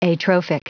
Prononciation du mot atrophic en anglais (fichier audio)
Prononciation du mot : atrophic